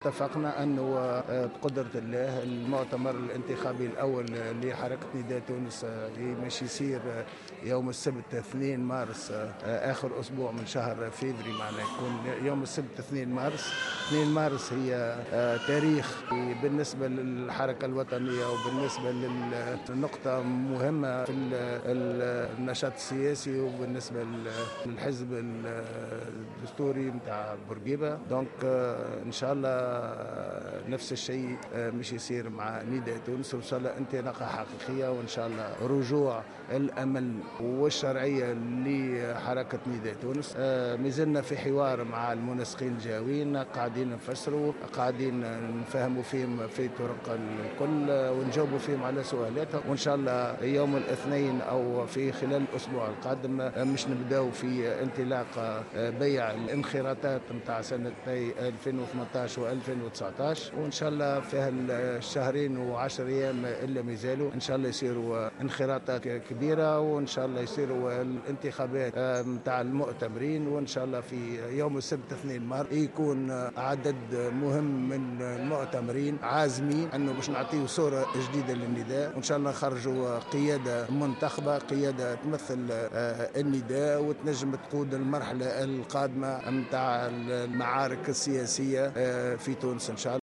وأكد في تصريح لمراسل "الجوهرة أف ام" أهمية هذا التاريخ بالنسبة للحركة الوطنية في علاقة بالحزب الدستوري مشيرا إلى الزعيم الحبيب بورقيبة.
وجاءت تصريحاته على هامش أشغال الندوة الوطنية للمنسقين الجهويين لحركة نداء تونس المنعقدة حاليا في المهدية.